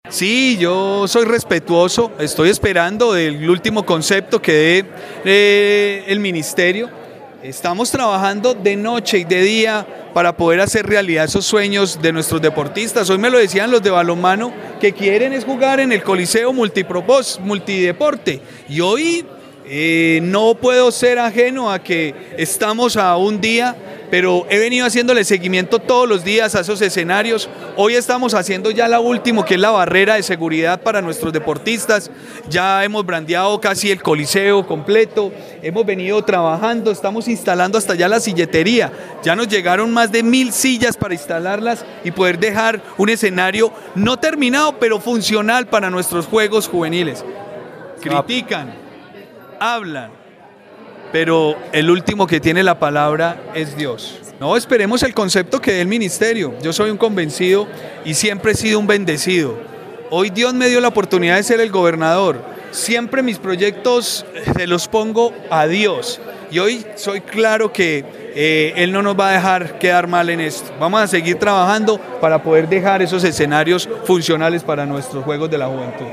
Juan Miguel Galvis, gobernador Quindío